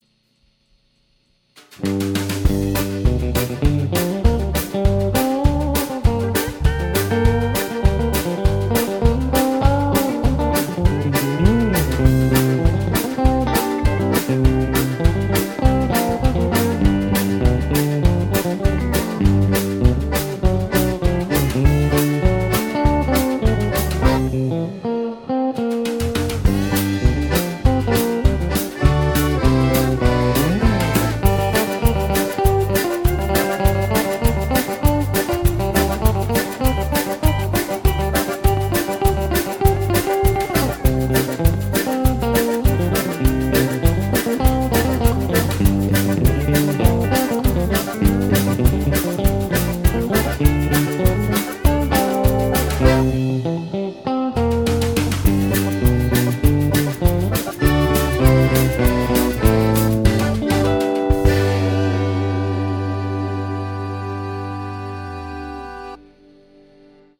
Mukavaa pyörittelyä, taustan ehdoilla mentiin.
Soundi oli hiukka vaatimaton ja turhan taustalle miksattu.